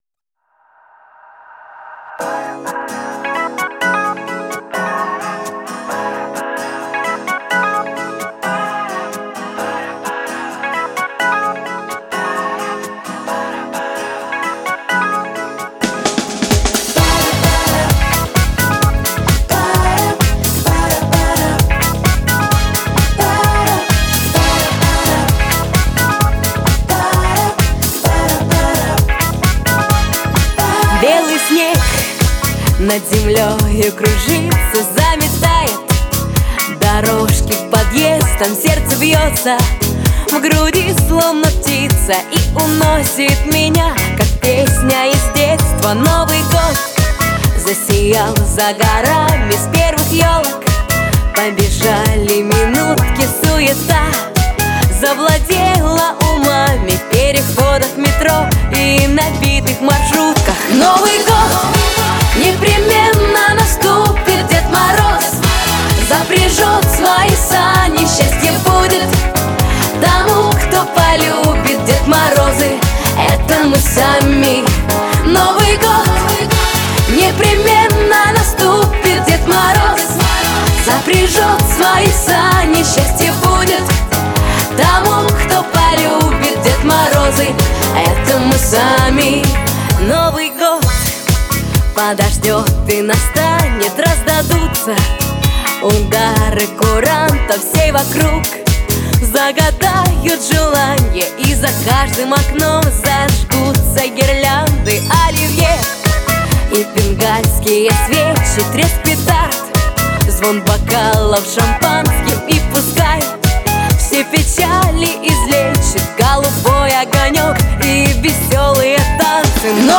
наполнен энергией и задором